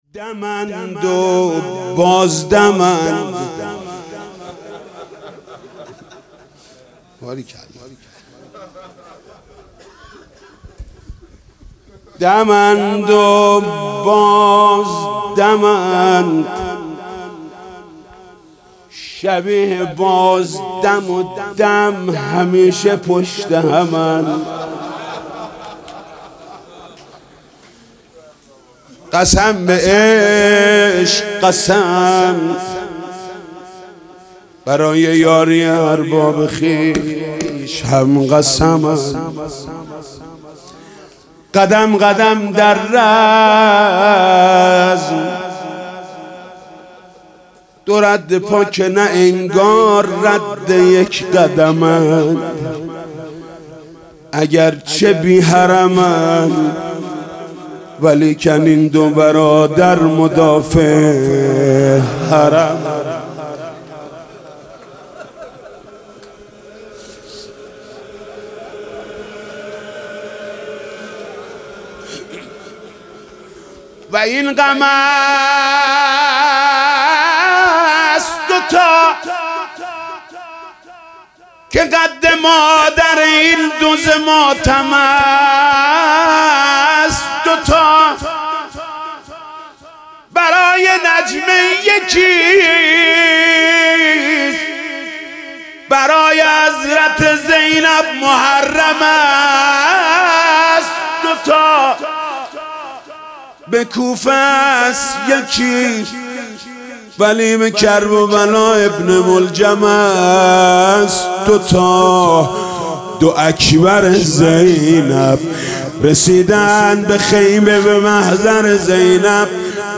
محرم96 - روضه - دم اند و بازدمند